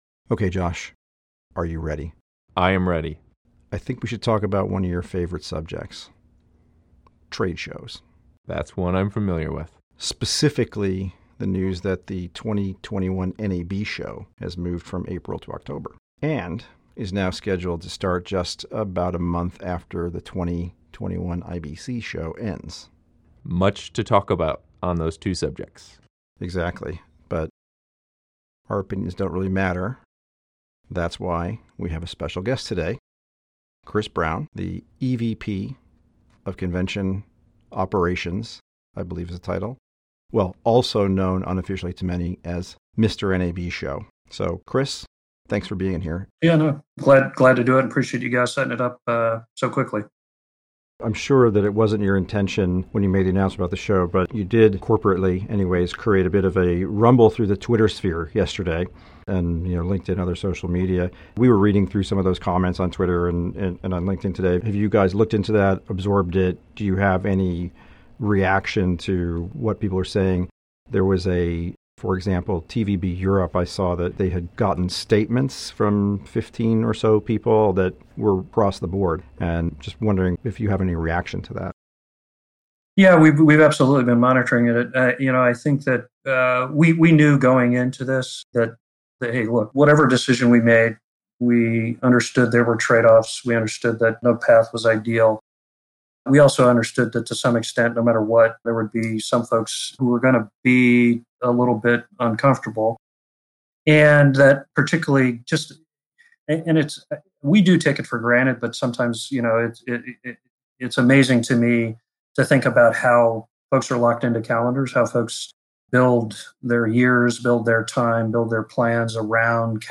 This candid discussion covers a lot of ground.